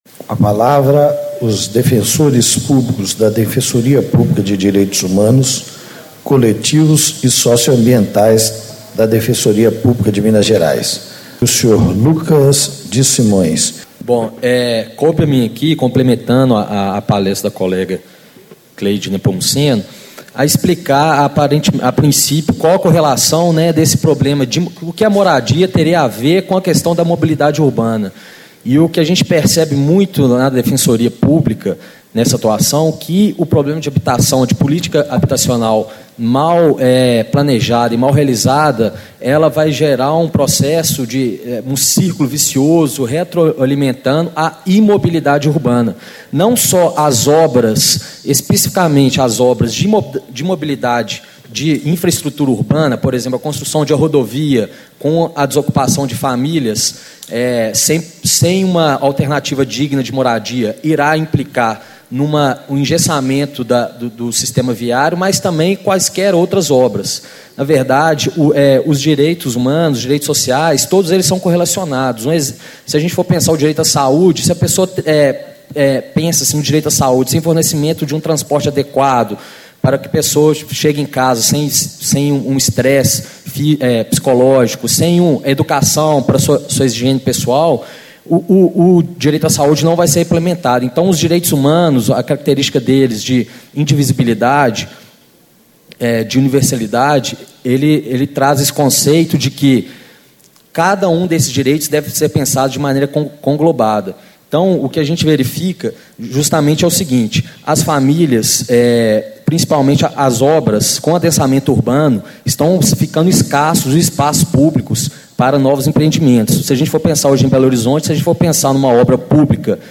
Painel: Gestão e Planejamento da Mobilidade Urbana - Lucas Diz Simões - Defensor Público da Defensoria Pública de Direitos Humanos, Coletivos e Socioambientais
Discursos e Palestras